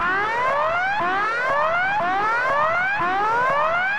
Alarm 1 Loop.wav